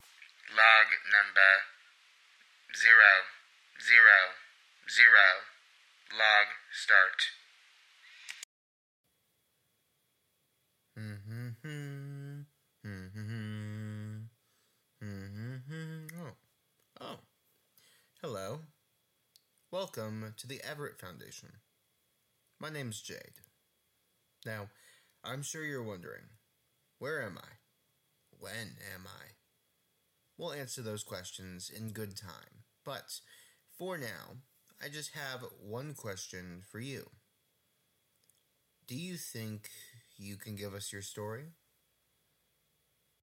Format: Audio Drama
Writing: Scripted Voices: Full cast
Soundscape: Sound effects